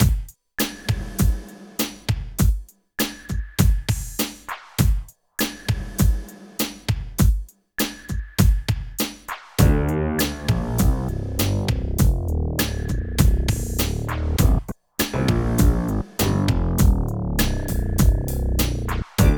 12 LOOP A -R.wav